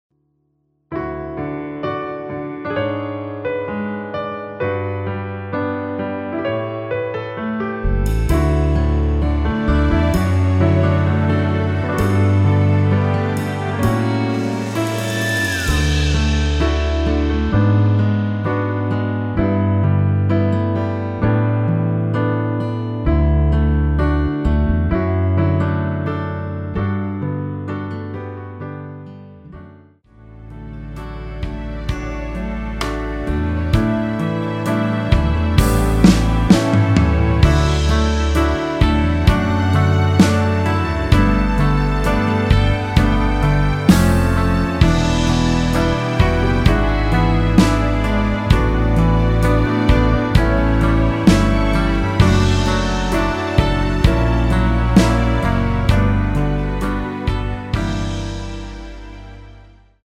(-1) 내린 MR 입니다.(미리듣기 참조)
◈ 곡명 옆 (-1)은 반음 내림, (+1)은 반음 올림 입니다.
앞부분30초, 뒷부분30초씩 편집해서 올려 드리고 있습니다.